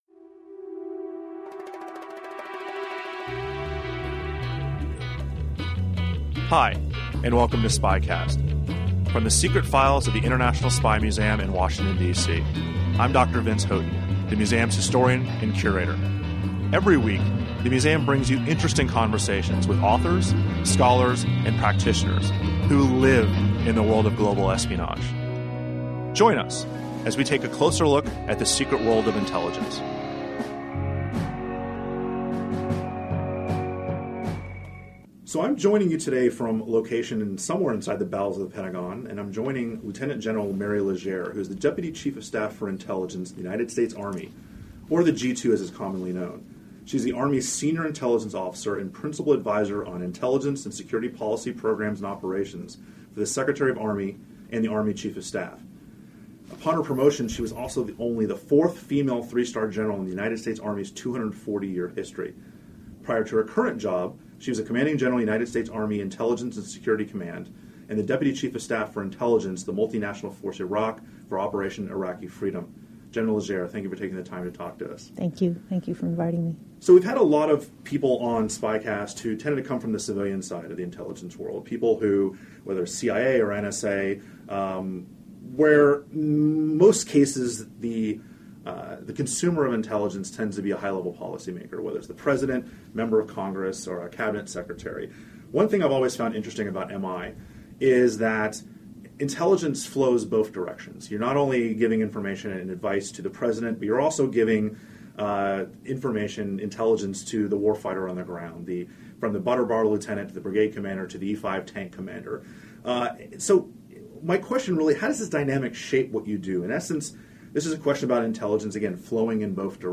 Army Intelligence: A View from the Top. An Interview with the Chief of Army Intel, LTG Mary Legere